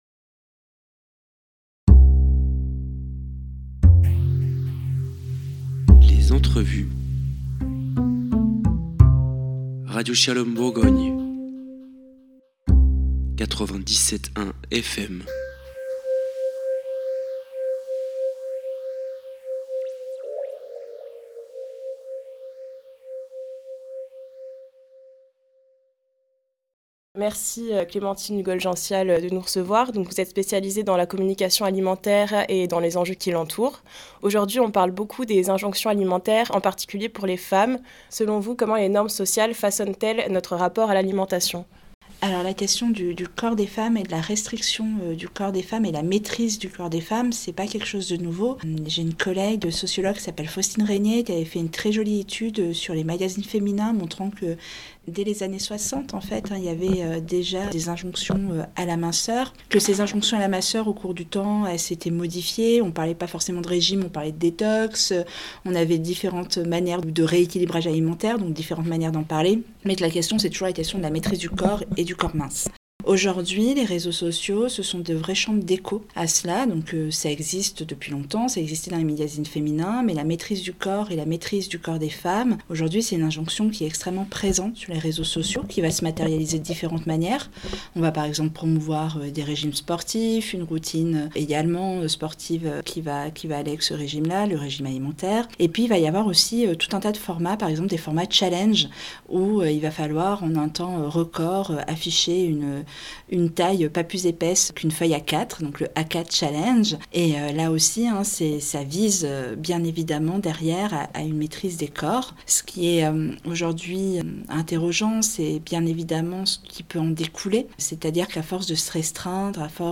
Lors de notre entretien, nous avons abordé ces tensions sociétales, la place du plaisir dans l’alimentation et l’impact des réseaux sociaux sur la perception du corps féminin.